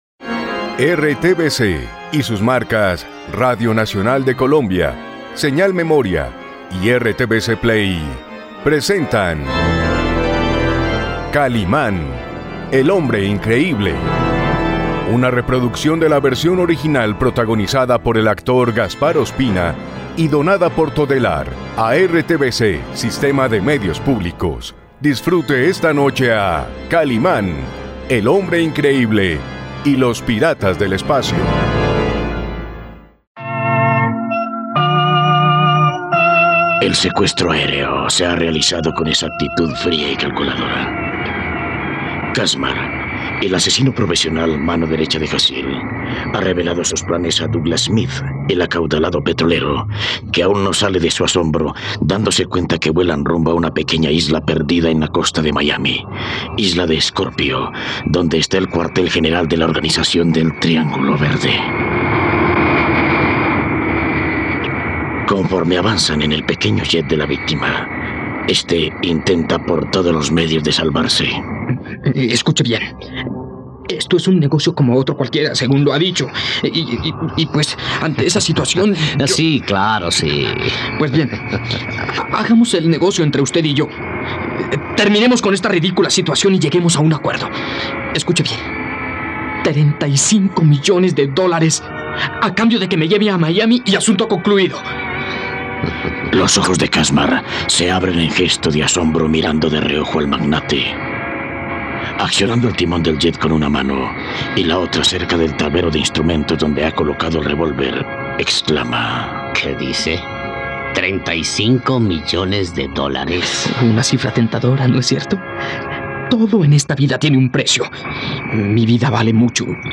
..No te pierdas la radionovela completa de Kalimán y los piratas del espacio.